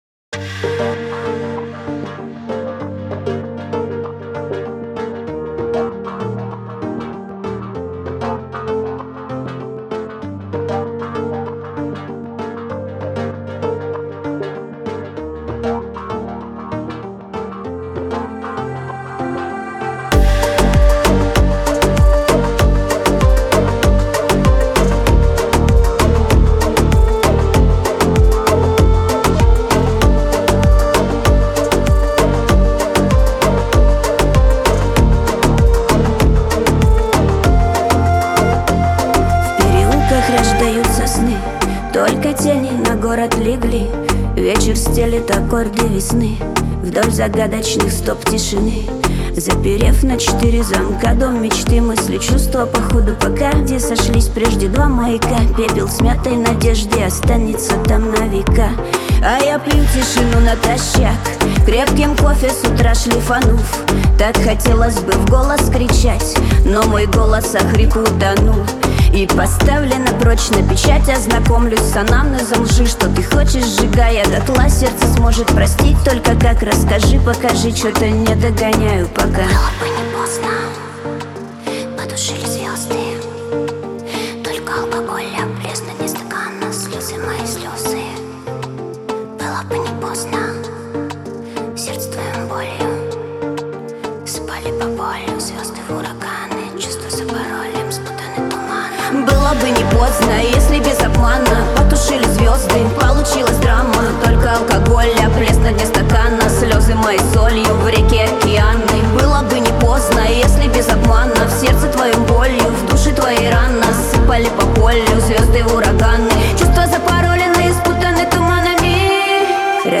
Шансон , грусть , Лирика